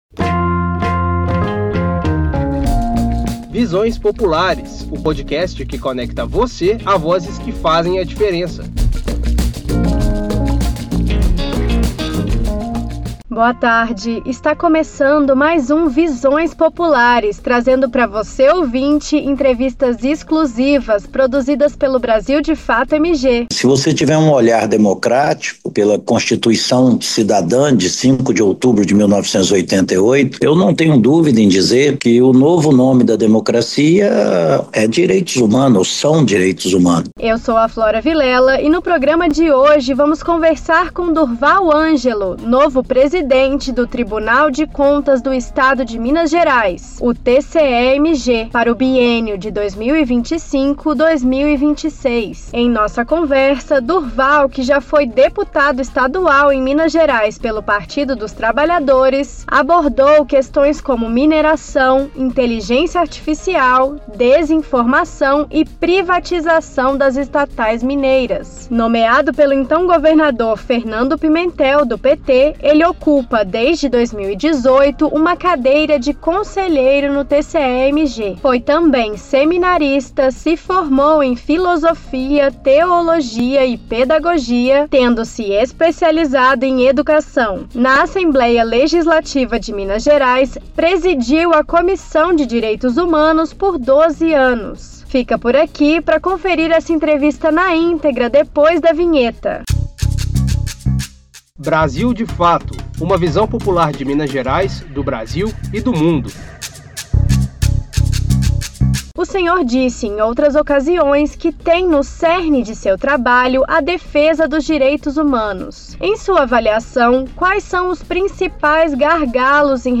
Entrevista exclusiva com Durval Ângelo, presidente do TCE-MG, sobre mineração, inteligência artificial e direitos humanos.